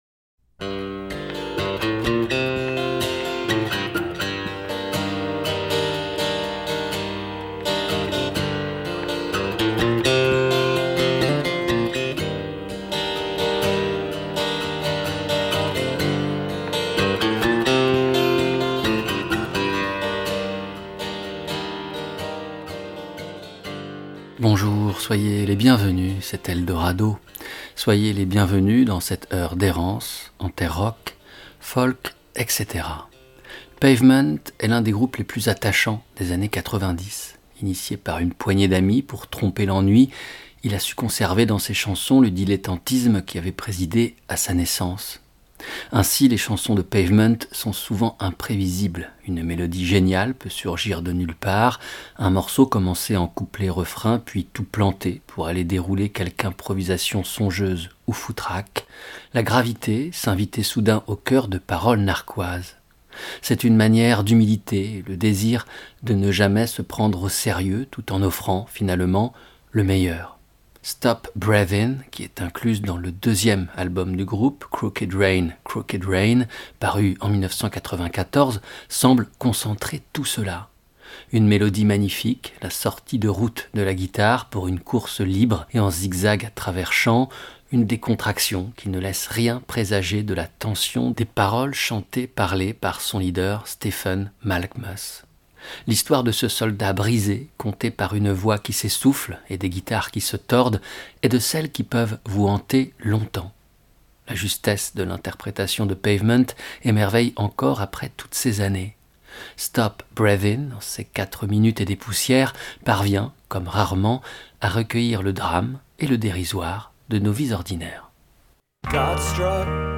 RADAR, La Radio d'Art en Sort